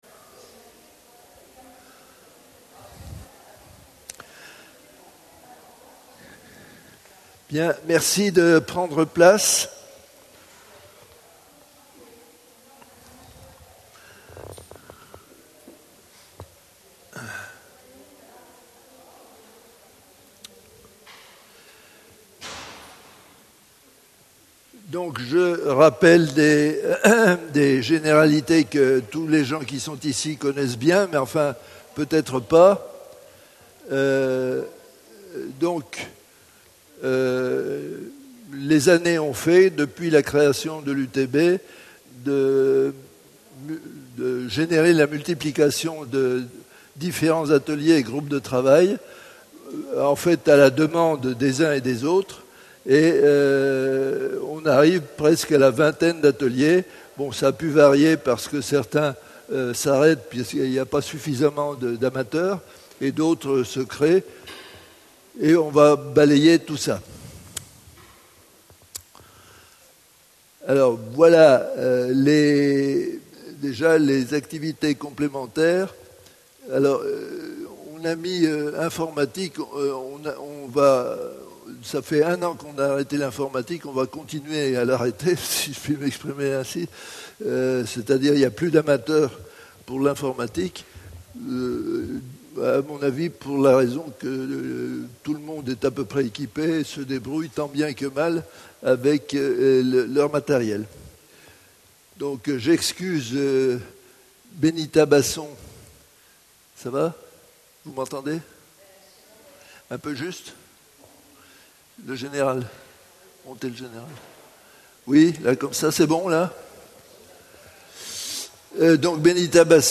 Réunions d'information